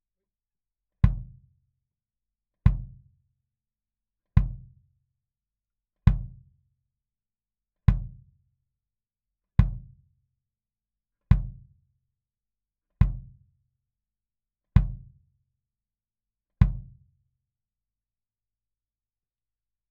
本書制作時に収録の非圧縮オーディオファイル（24bit /96kHz）です。
ドラムレコーディングのTips
キックからの距離によって変化するポイントキック（with KickPad）
1. “キック（with KickPad）キックから46cmの位置に設置したもの”
02Kick_Far_KickPad_01.wav